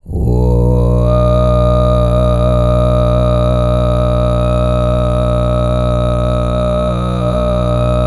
TUV5 DRONE02.wav